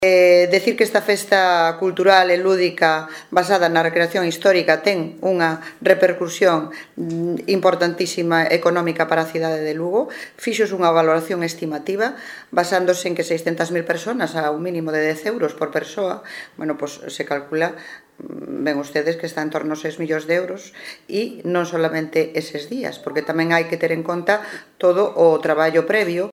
Así lo ha asegurado la concelleira Carme Basadre durante la rueda de prensa que ha ofrecido en la mañana de este martes para hacer balance de la fiesta de ambientación histórica que acaba de terminar.